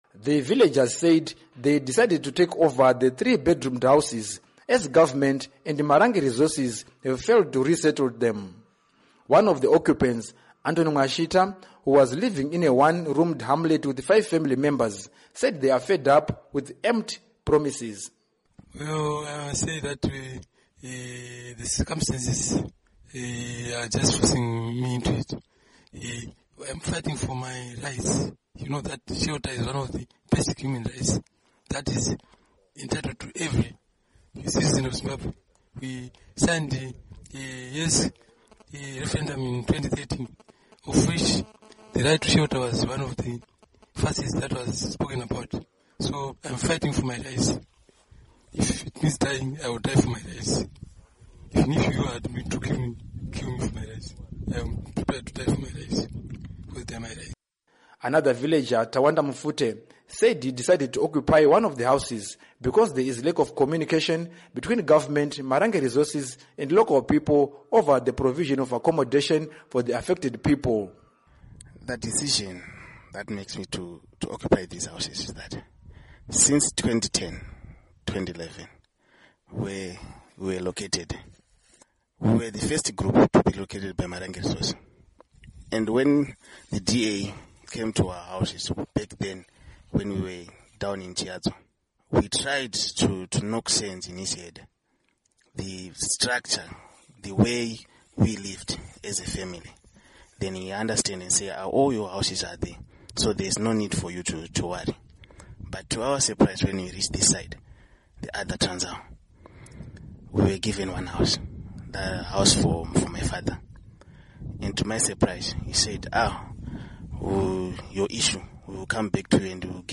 Report On Arda Invasions